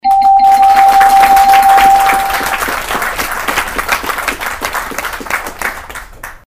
(dings; audience applause)
sfxapplausedings.mp3